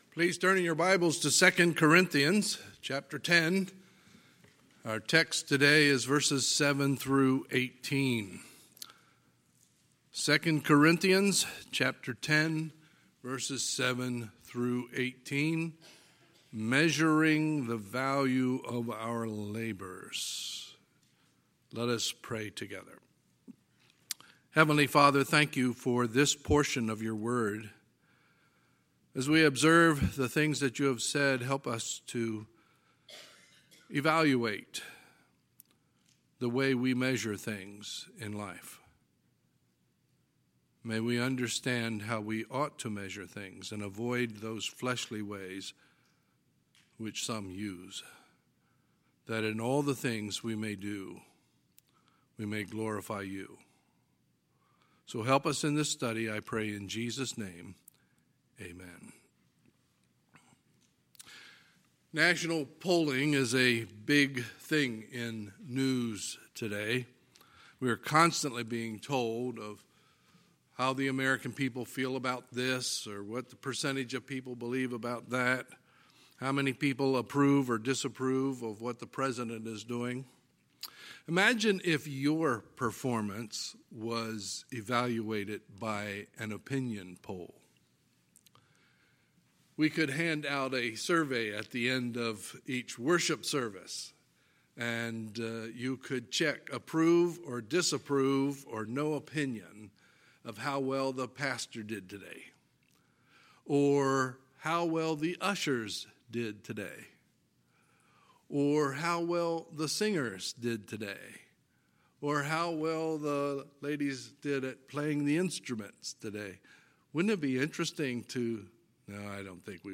Sunday, October 4, 2020 – Sunday Morning Service